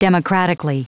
Prononciation du mot democratically en anglais (fichier audio)
democratically.wav